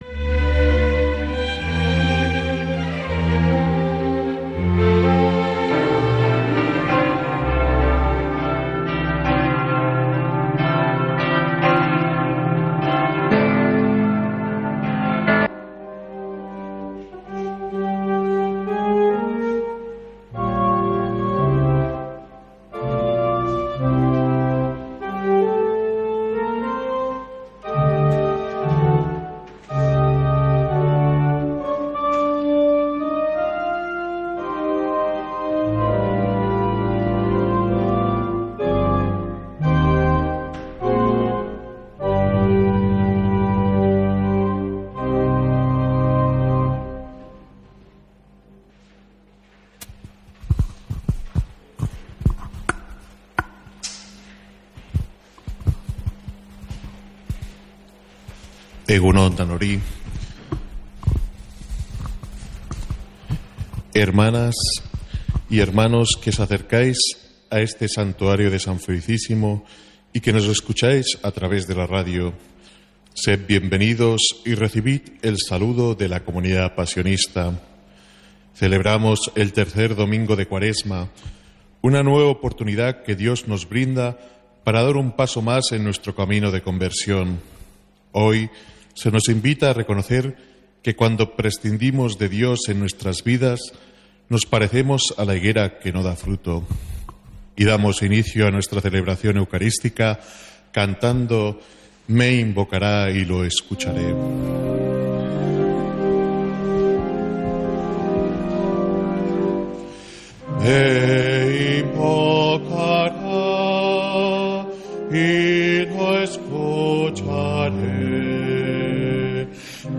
Santa Misa desde San Felicísimo en Deusto, domingo 23 de marzo